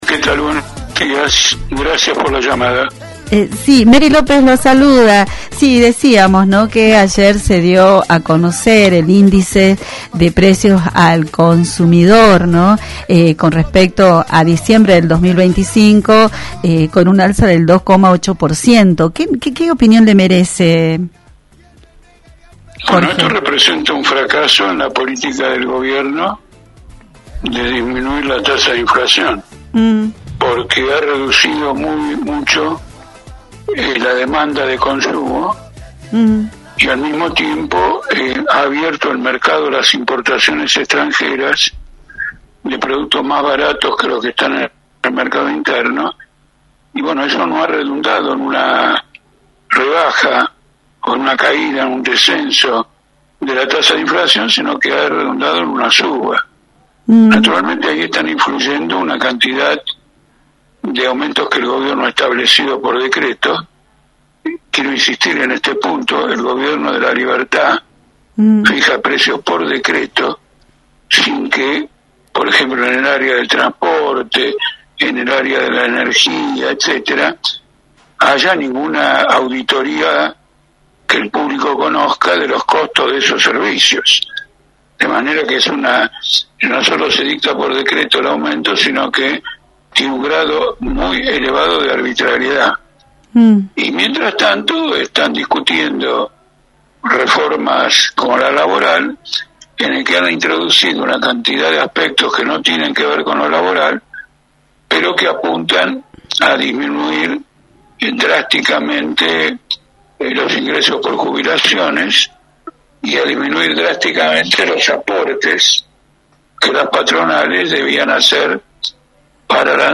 En conversación con Radio Cadena Máxima, Jorge Altamira, referente de Política Obrera, analizó el índice de precios al consumidor correspondiente a diciembre, que marcó un aumento del 2,8%.